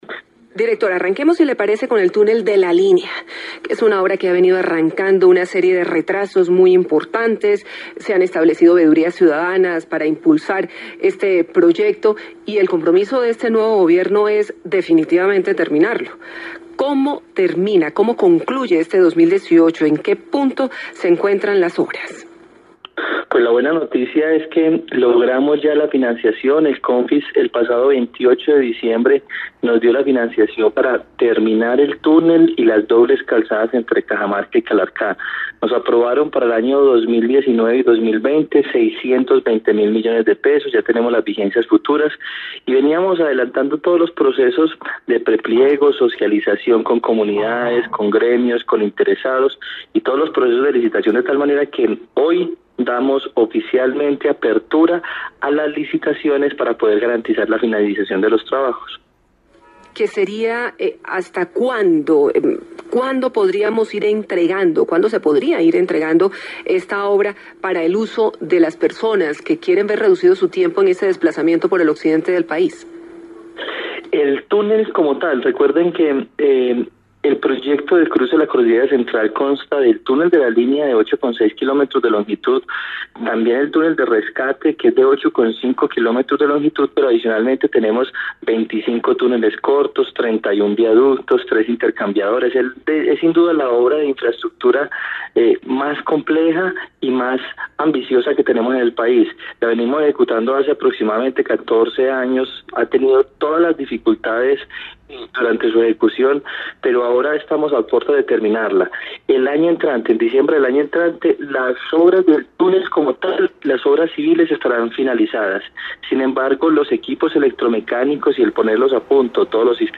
En diálogo con Caracol Radio el director del Invías, Juan Esteban Gil, indicó que se aprobaron $620.000 millones para concluir la obra.
AUDIO: Director del Invías, Juan Esteban Gil: